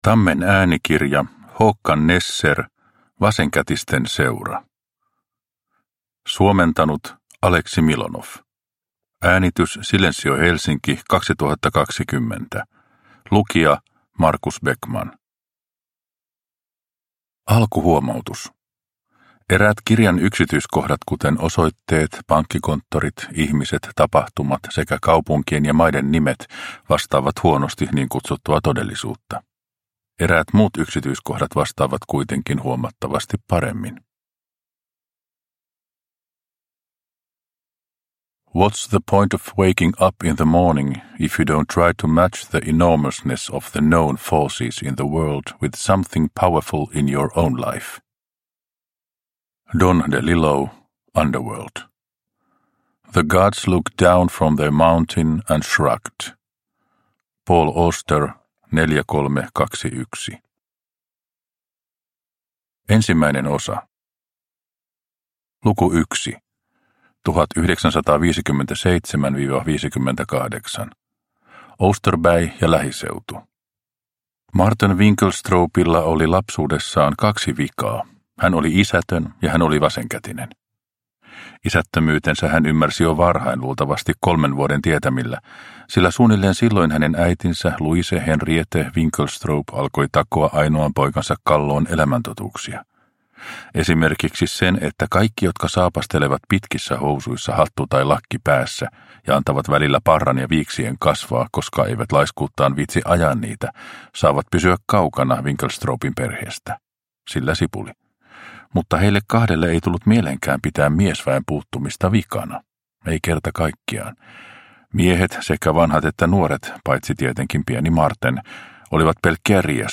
Vasenkätisten seura – Ljudbok – Laddas ner